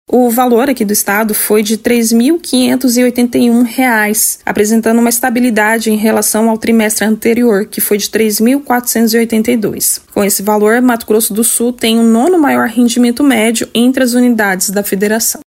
Em entrevista ao programa “Agora 104” da FM Educativa MS